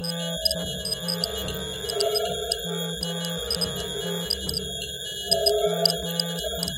其他 " 机械人/机器呼吸
描述：一个喜欢呼吸声的机器人。
Tag: 机器人 呼吸 人工 机器人 机械 电子人 技术 外来